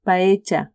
paella /paetcha/